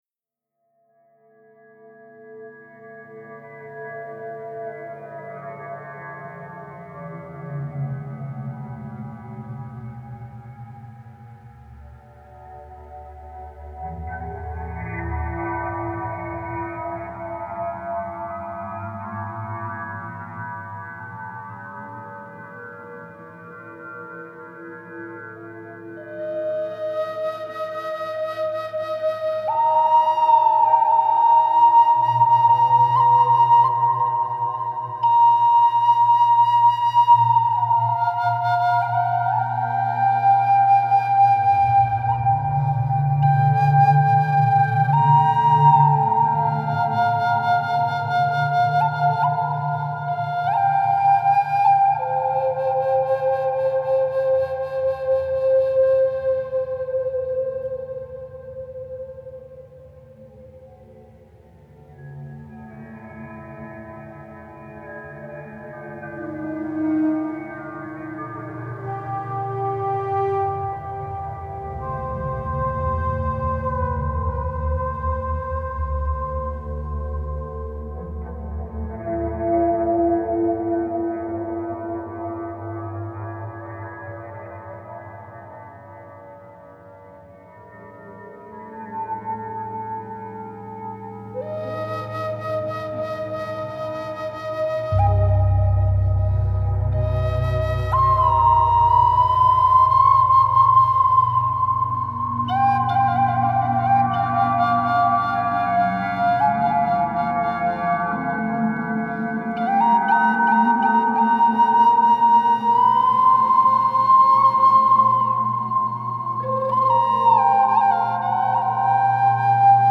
Studio recording
flute
tabla tarang
udu drum
windsynth
bass
ambient bed